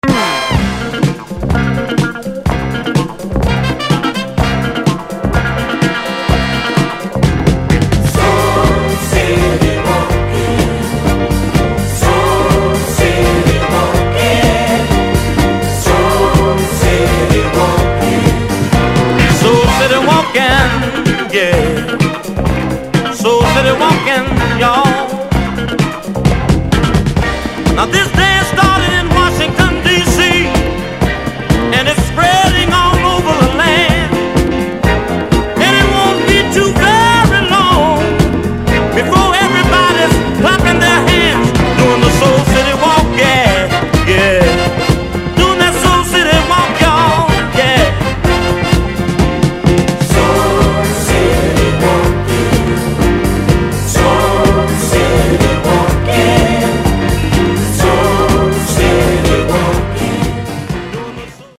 Album未収録の爽やかPhilly Soul！